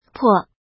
怎么读
po
po5.mp3